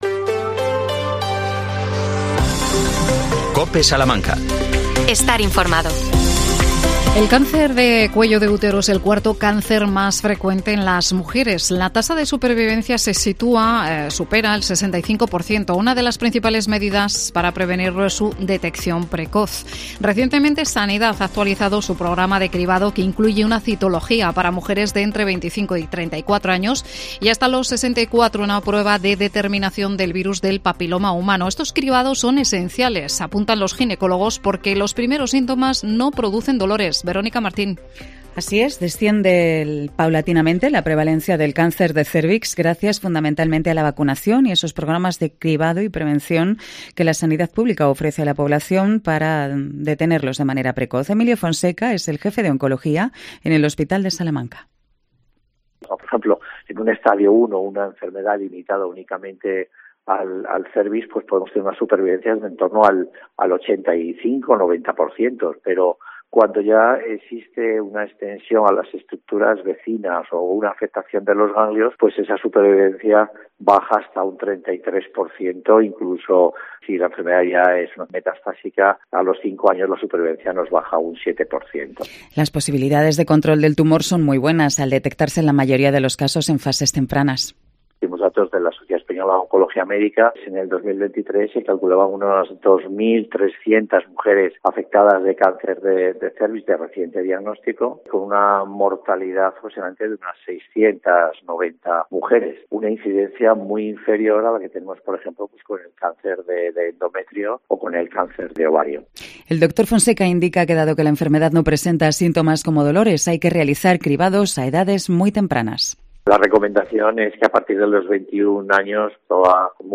AUDIO: Cáncer de cérvix: declaraciones de ginecólogo, pediátra y psicóloga.Nueva gerencia en el CAUSA.